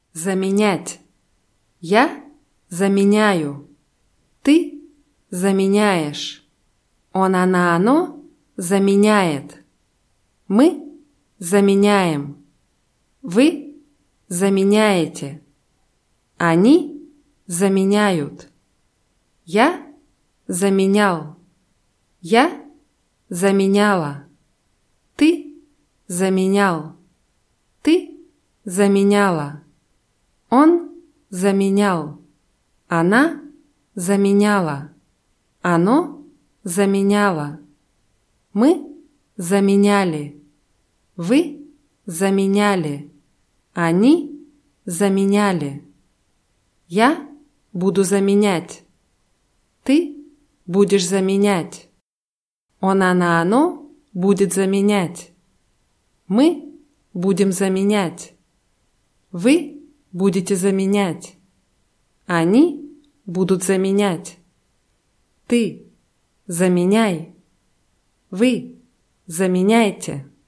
заменять [zamʲinʲátʲ]